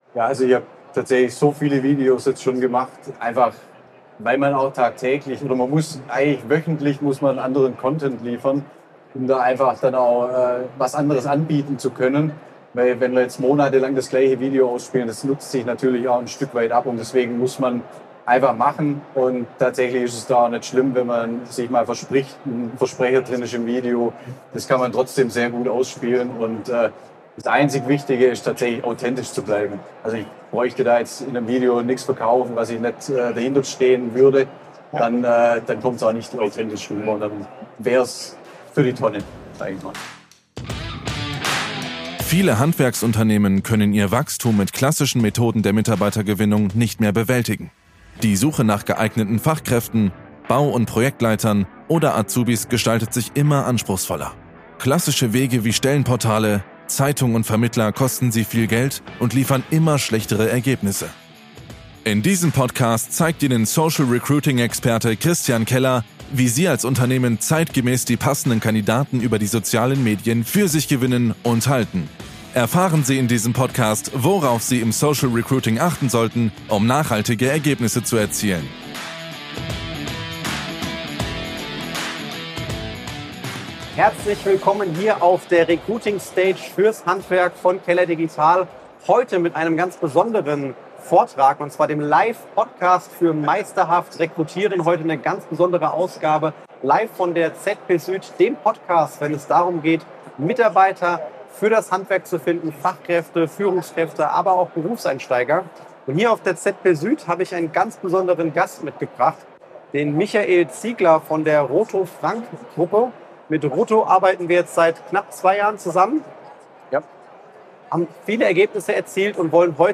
Kundeninterview mit RoTo Frank